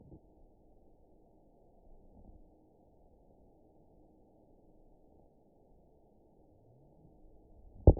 event 920158 date 03/01/24 time 08:57:49 GMT (1 year, 9 months ago) score 7.49 location TSS-AB04 detected by nrw target species NRW annotations +NRW Spectrogram: Frequency (kHz) vs. Time (s) audio not available .wav